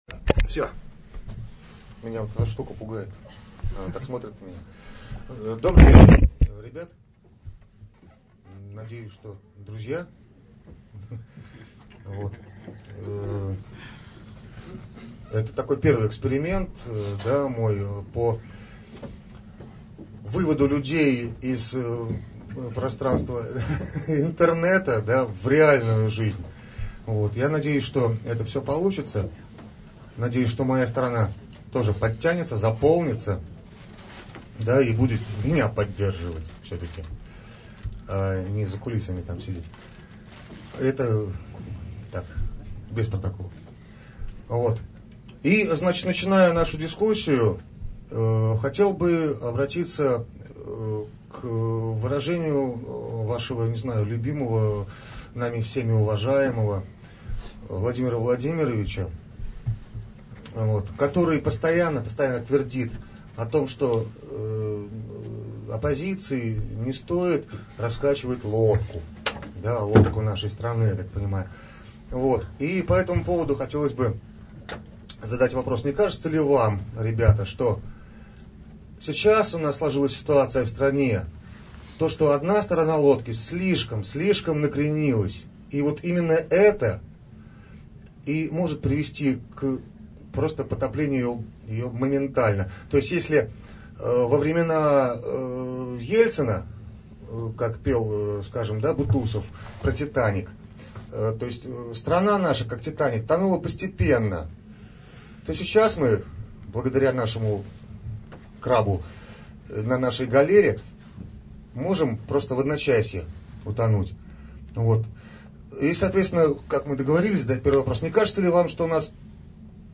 diskussija_s_liberalami.mp3